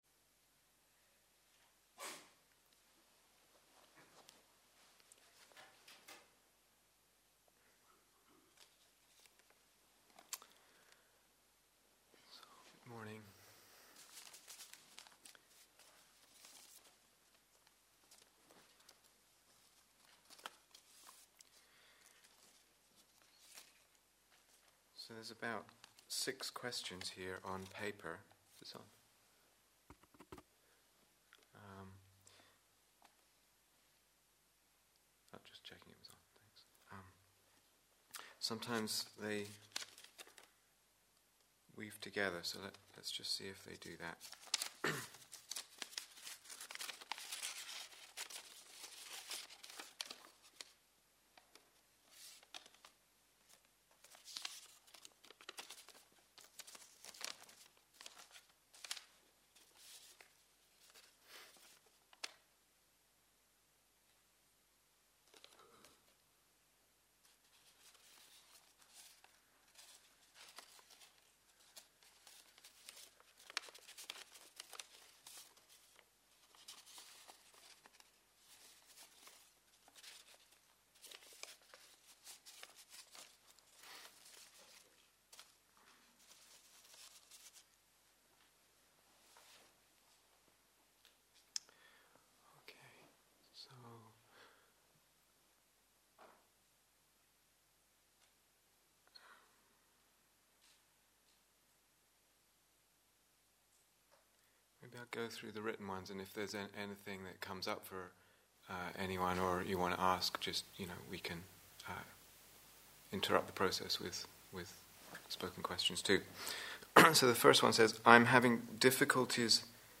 Question and Answer Session